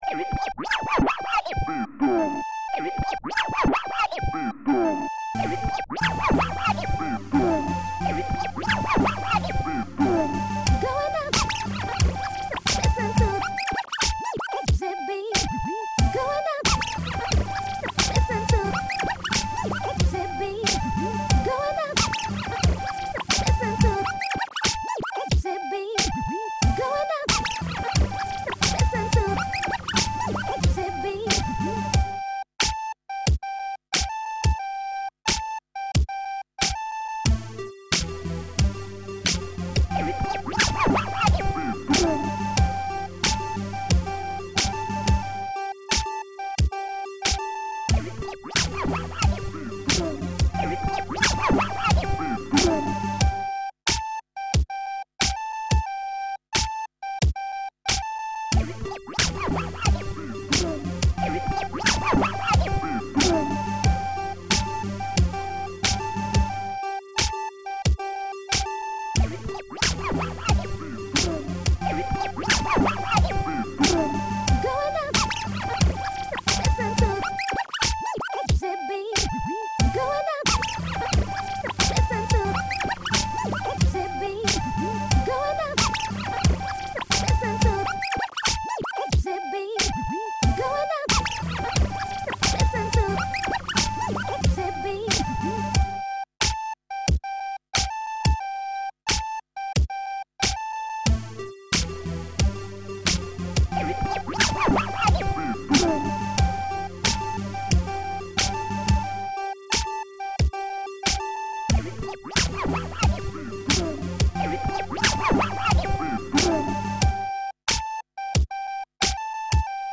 Зацените минус, по-моему неплохо получилось, очень рад буду конструктивной критике... итак качаем:
Нее...мэн не катит,какие то пищалки,звучит плохо...скрейч на чём делал?
PrettyBeat.mp3